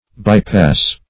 By-pass \By"-pass\, n. (Mech.)